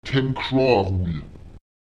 Lautsprecher 764 [tEmÈk¨aùruùl] 500